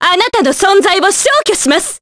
Veronica-Vox_Skill5_jp.wav